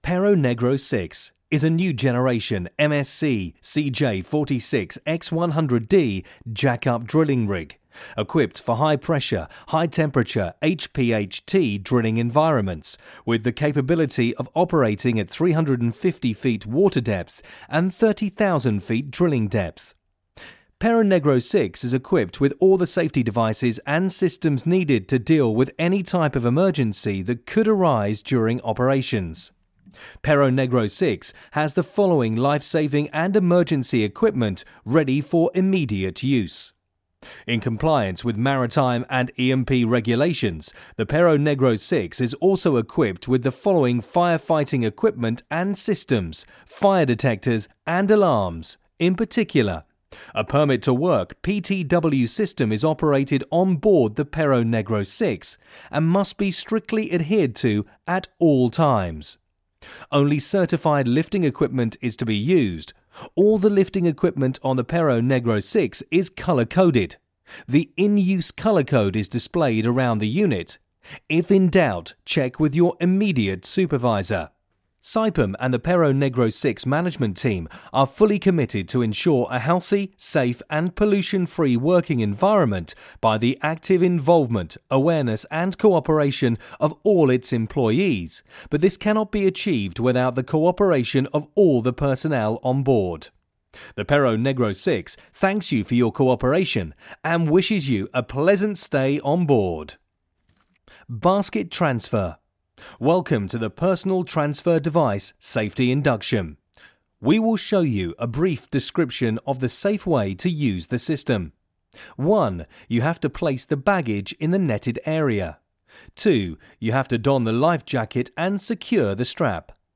Perro Negro 6 Voice Over